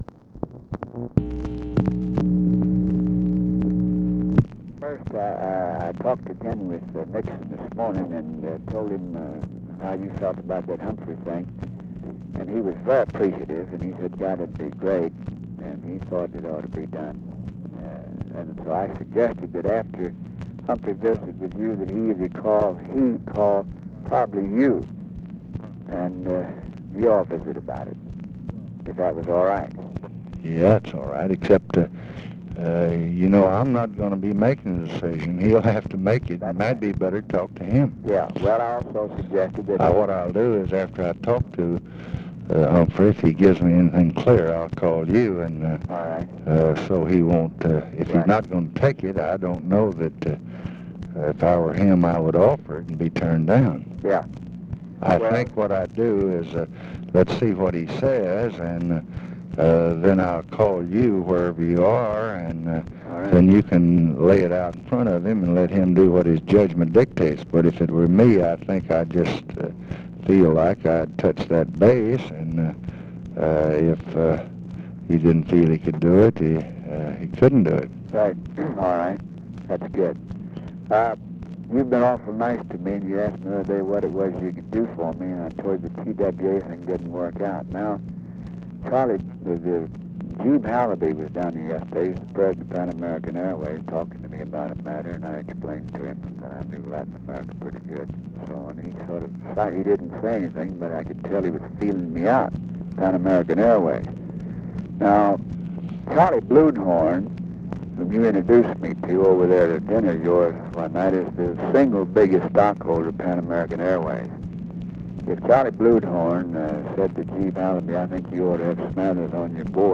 Conversation with GEORGE SMATHERS, November 22, 1968
Secret White House Tapes